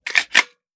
12ga Pump Shotgun - Load Shells 004.wav